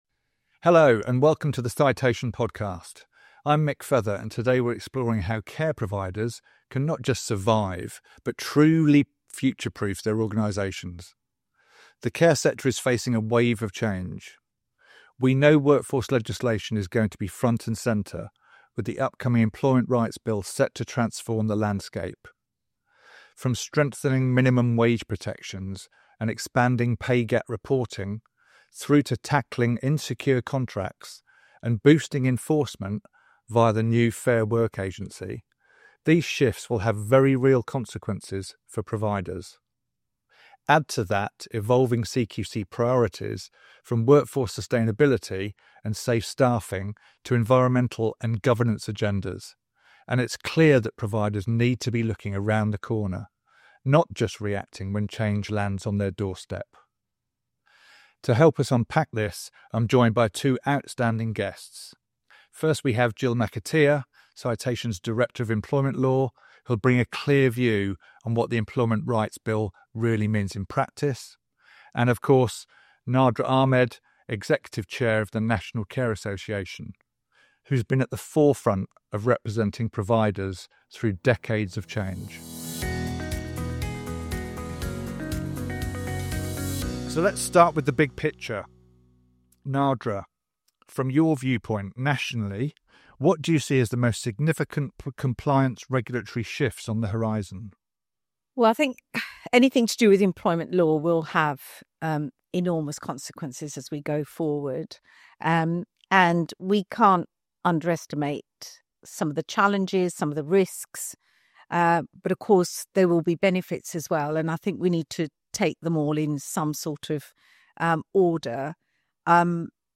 A forward-looking care sector discussion on workforce law, the Employment Rights Bill, CQC expectations and how providers can move from firefighting to proactive compliance.